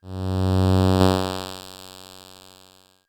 ihob/Assets/Extensions/RetroGamesSoundFX/Hum/Hum16.wav at master
Hum16.wav